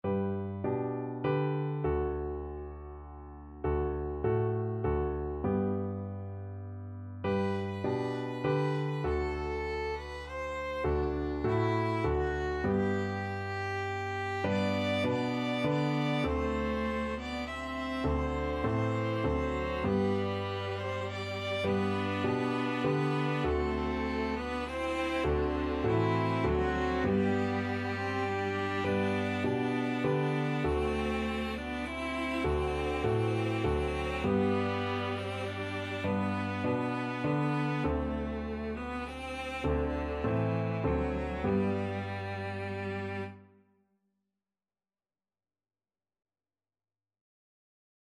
Free Sheet music for Piano Quartet
ViolinViolaCelloPiano
3/4 (View more 3/4 Music)
G major (Sounding Pitch) (View more G major Music for Piano Quartet )
Piano Quartet  (View more Easy Piano Quartet Music)
Traditional (View more Traditional Piano Quartet Music)
music_alone_shall_live_PNQ.mp3